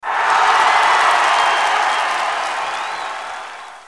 eff_happy.mp3